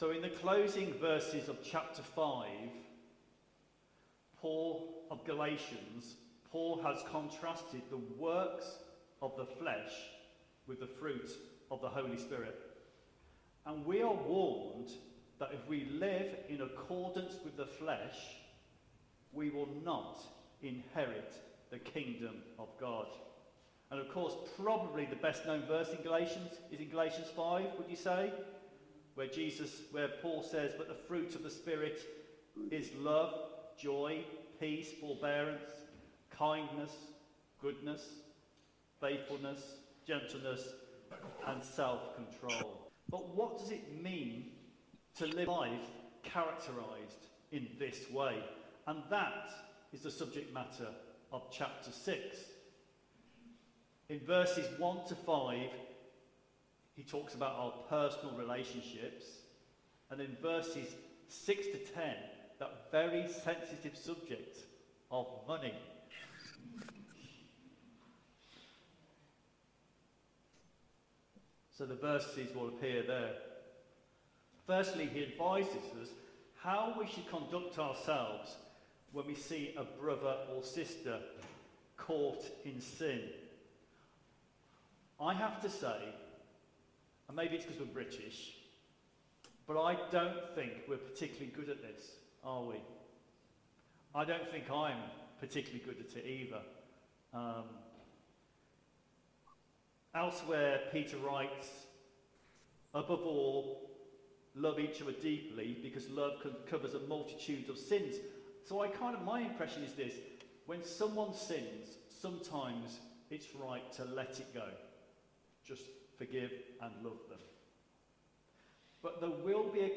Media for Holy Communion on Sun 06th Jul 2025 09:00 Speaker